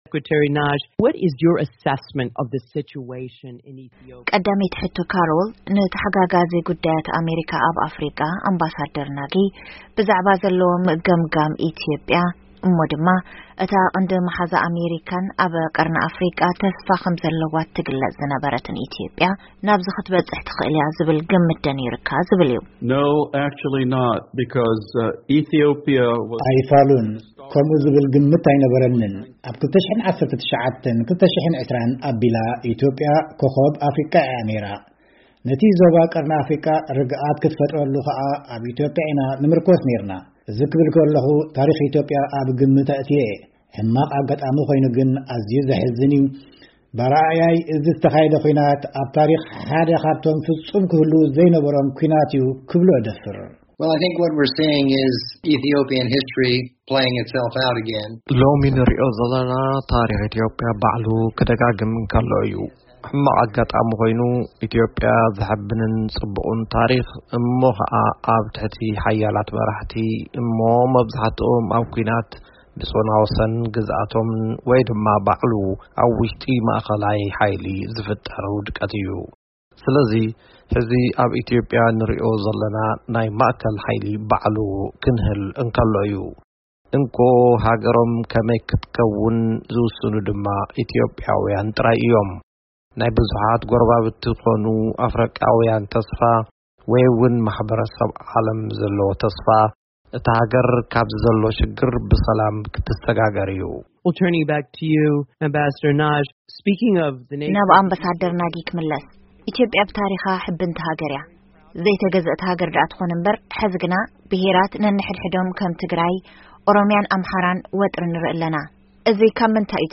ዘተ ኣብ ጉዳይ ኢትዮጵያ